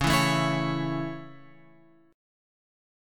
C#m#5 chord {9 7 7 9 x 9} chord